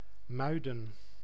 Muiden (Dutch pronunciation: [ˈmœydə(n)]
Nl-Muiden.ogg.mp3